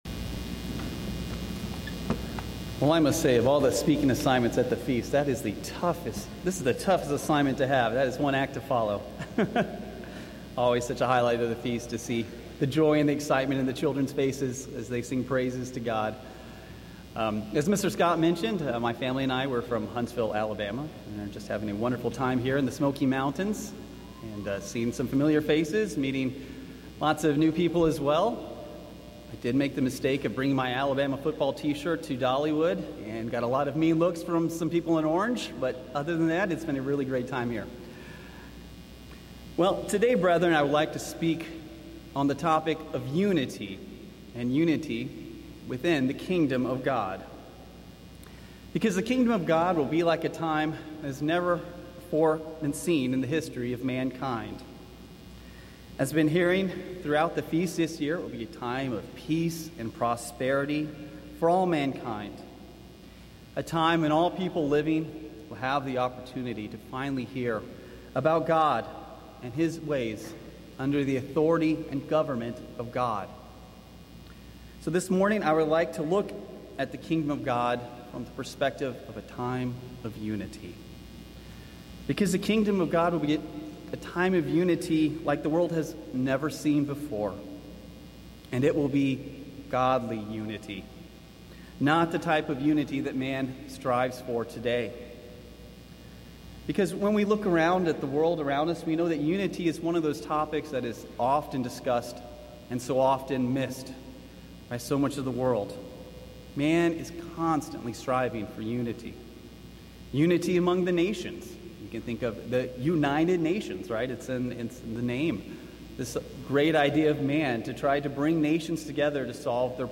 This sermon was given at the Gatlinburg, Tennessee 2023 Feast site.